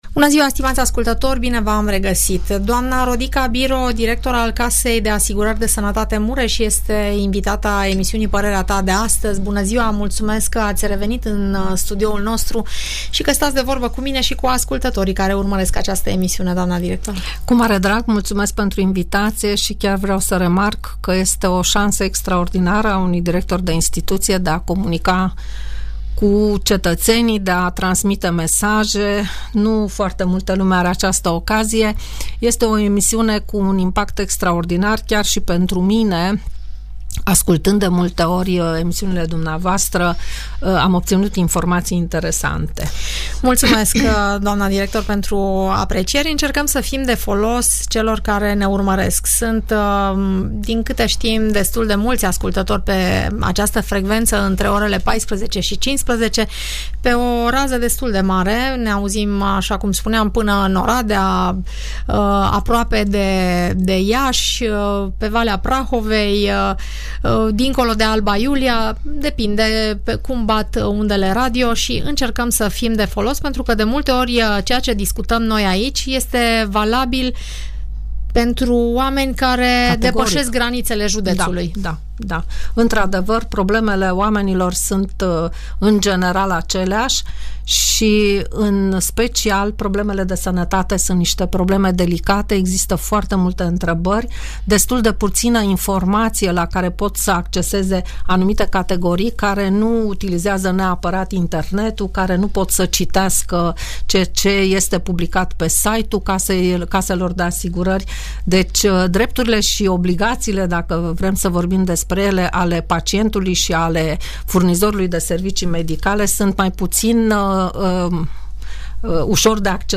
Audiență la Casa de Sănătate Mureș